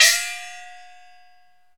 Index of /90_sSampleCDs/Roland L-CD701/PRC_Asian 2/PRC_Gongs